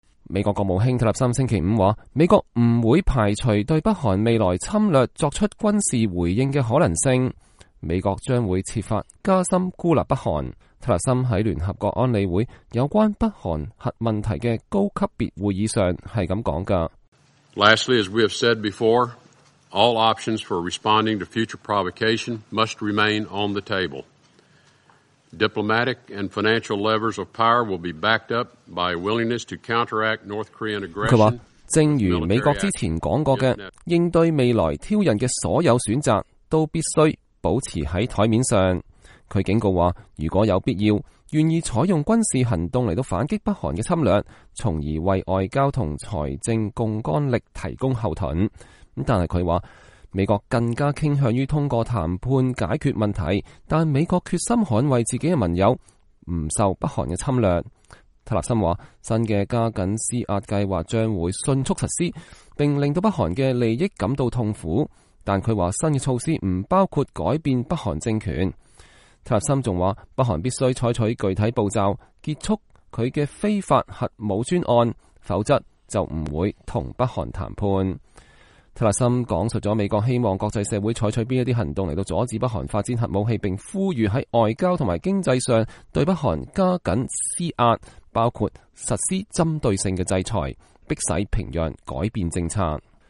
美國國務卿蒂勒森在安理會部長級會議上就北韓局勢發表講話（2017年4月28日）